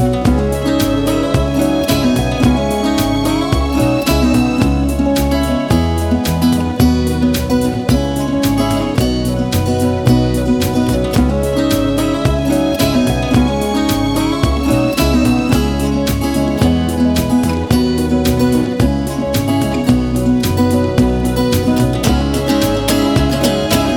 no Backing Vocals Country (Female) 2:43 Buy £1.50